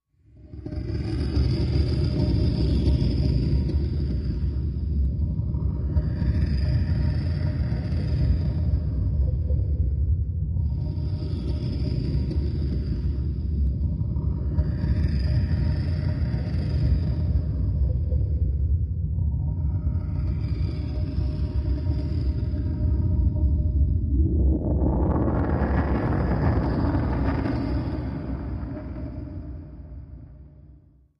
Deep Down Deep Dragging Cave Ambience